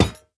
Minecraft Version Minecraft Version latest Latest Release | Latest Snapshot latest / assets / minecraft / sounds / block / vault / place3.ogg Compare With Compare With Latest Release | Latest Snapshot